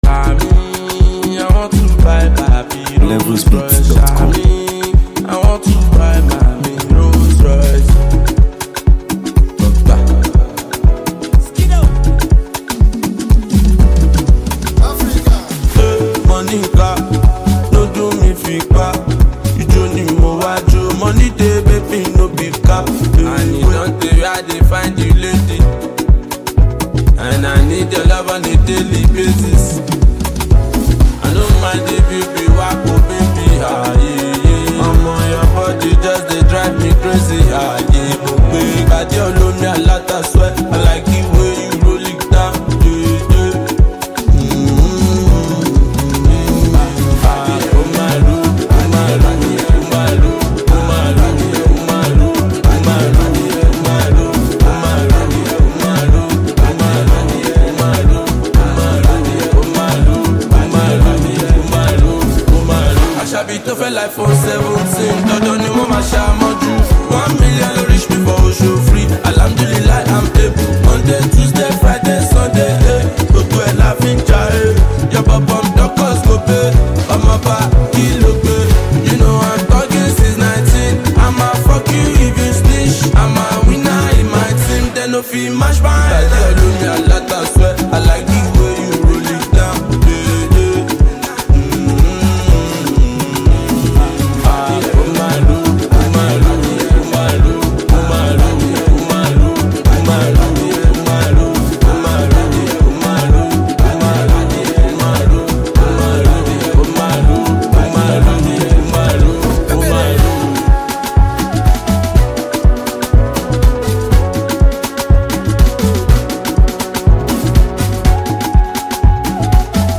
Nigeria Music
This energetic record